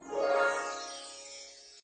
magic_harp_2.ogg